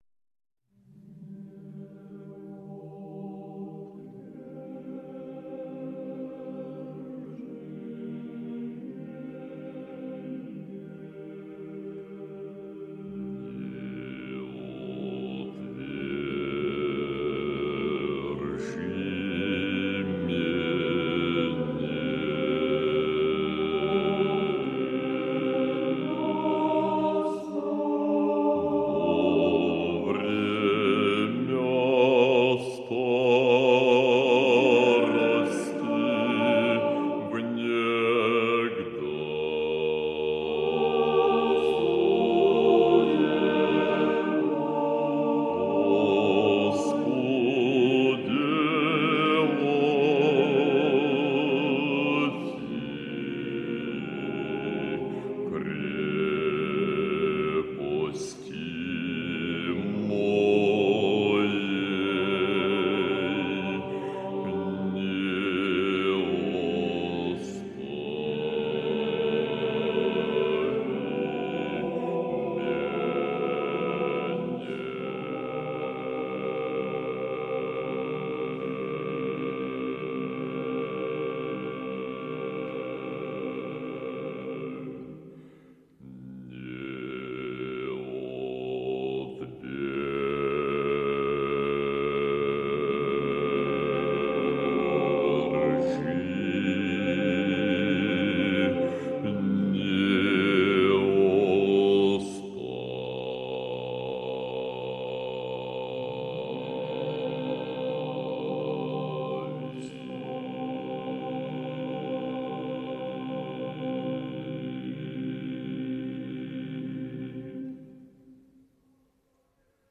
Отечественная духовная музыка
В своем концерте «Не отвержи мене во время старости» (стихи 9, 10, 11, 12 и 13 из псалма 70) Березовский даёт классический образец паралитургического концерта итальянского стиля в понимании русского православного, музыкально весьма образованного композитора.